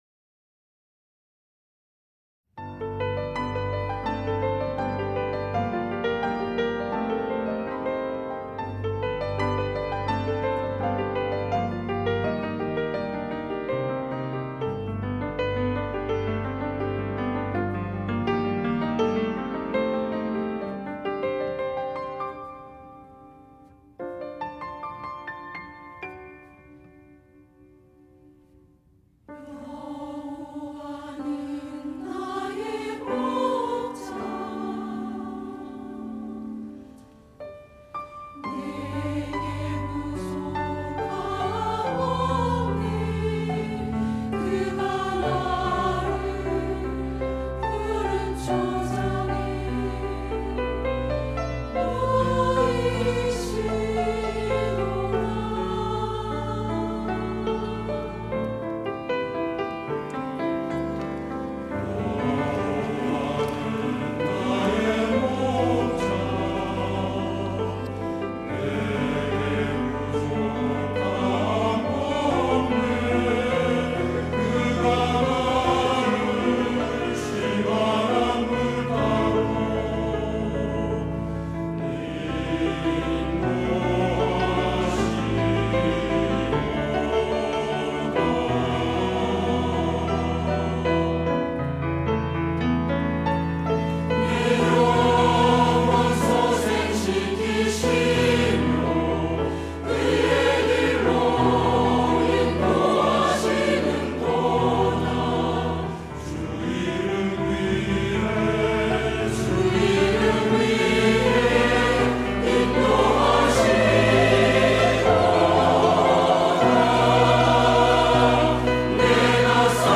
시온(주일1부) - 여호와는 나의 목자
찬양대